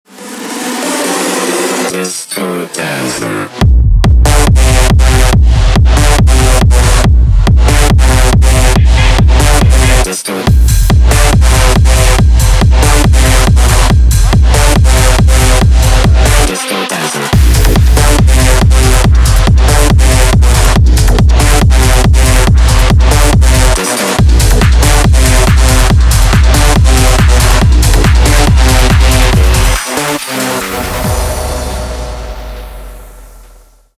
зарубежные клубные громкие с басами